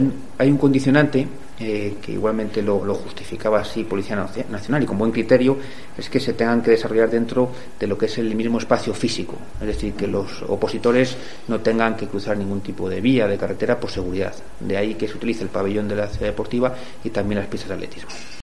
El portavoz del Ayuntamiento de Ávila, José Ramón Budiño